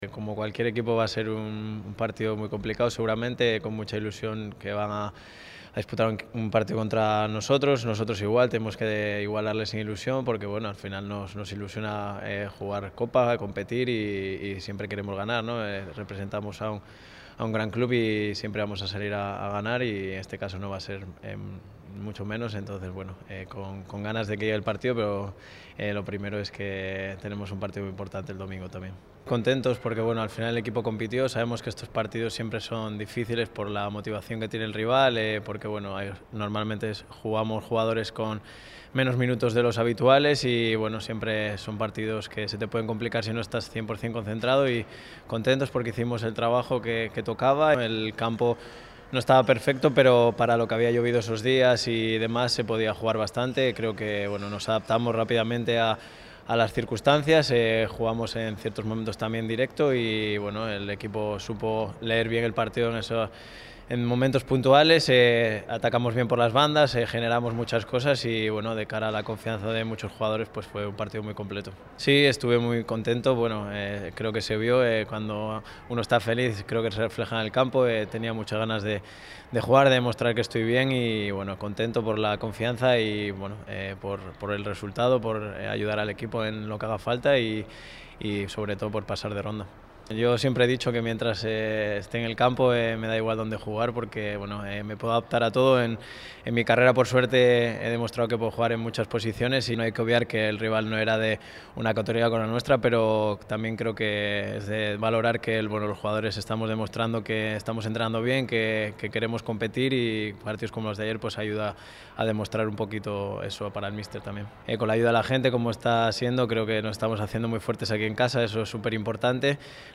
Jairo Samperio atendió a los medios del Málaga en la resaca del pase de ronda frente al Peña Sport.